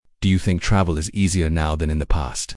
Part 3 (Discussion)